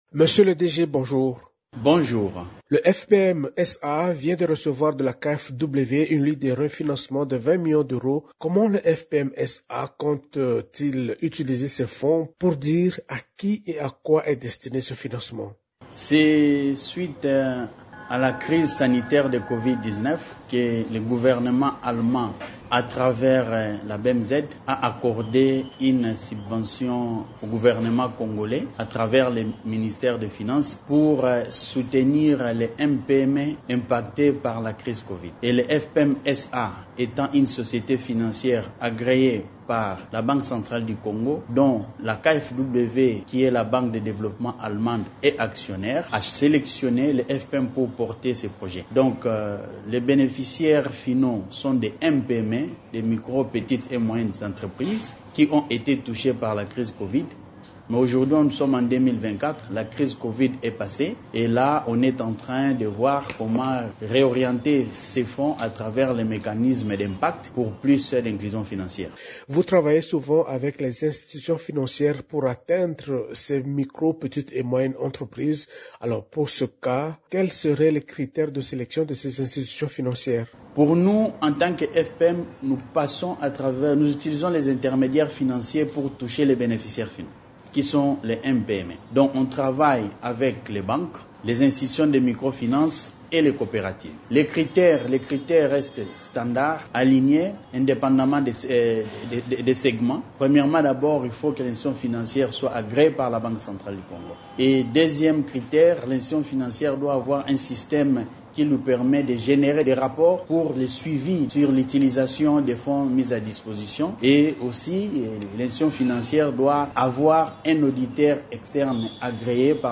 Dans une interview à Radio Okapi